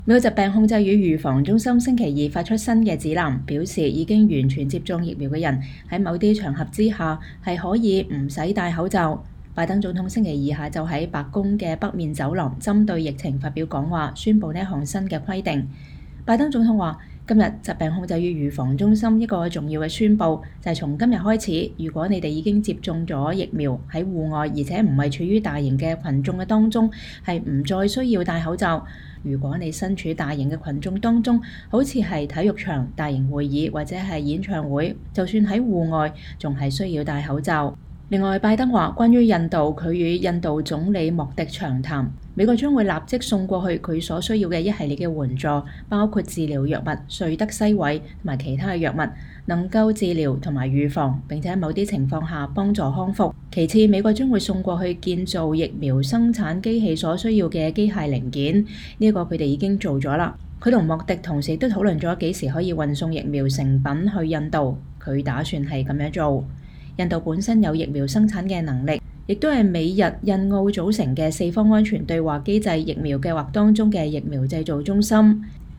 美國疾病控制與預防中心4月27號星期二發出新的指南，表示已經完全接種疫苗的人，在某些場合可以不必戴口罩。拜登總統星期二下午在白宮的北面走廊針對疫情發表講話，宣布這項新規定。